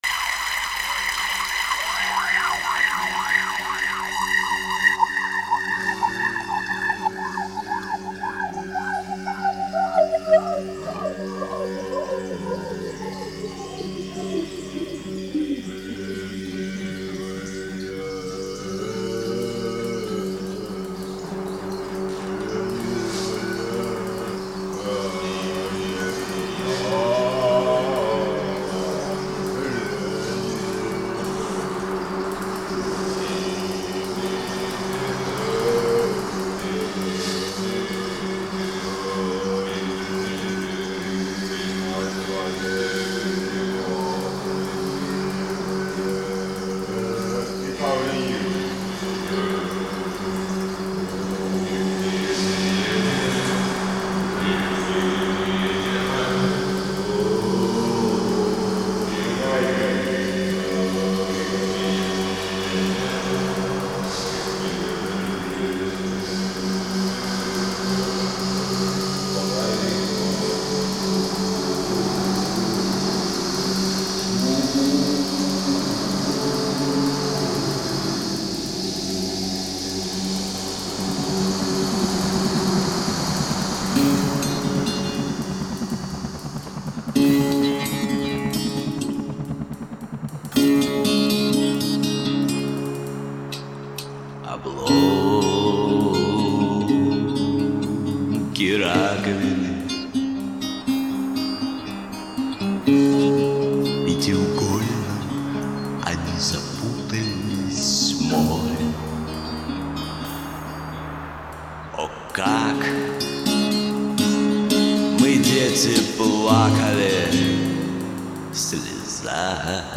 voix, guitare
machines, voix